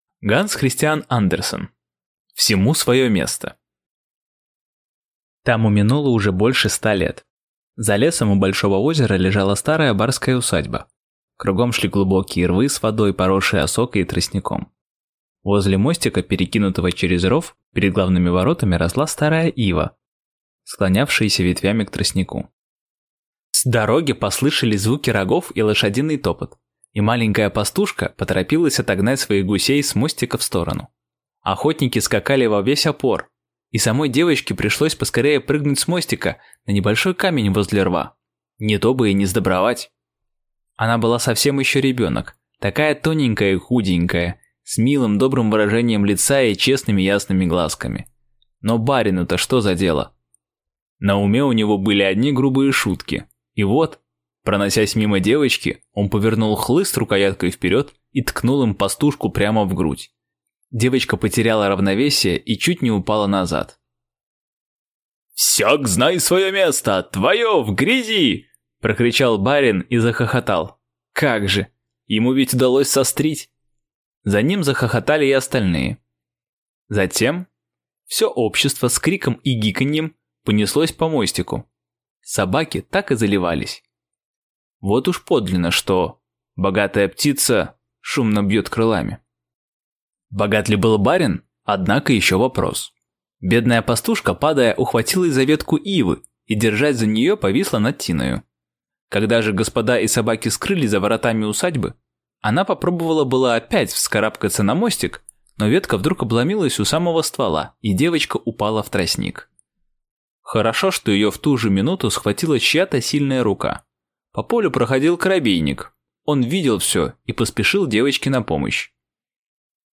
Аудиокнига Всему своё место | Библиотека аудиокниг